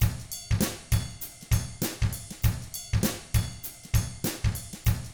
99FUNKY4T4-R.wav